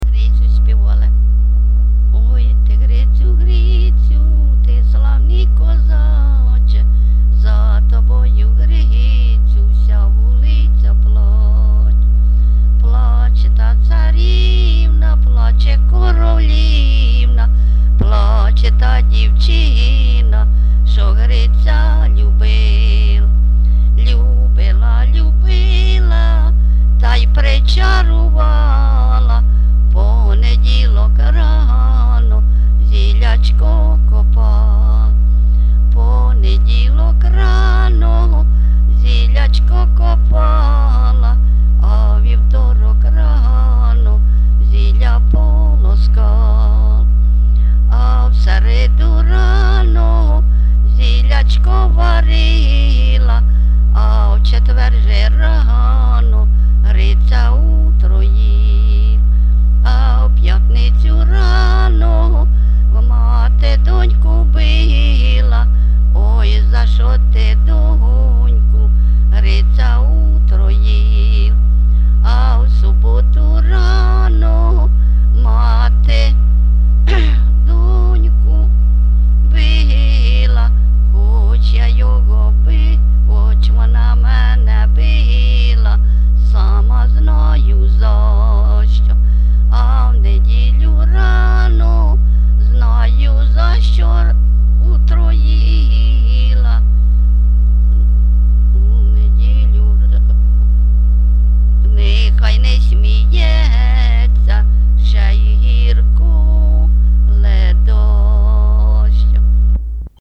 ЖанрПісні з особистого та родинного життя, Балади, Пісні літературного походження
Місце записус. Нижні Рівні, Чутівський район, Полтавська обл., Україна, Слобожанщина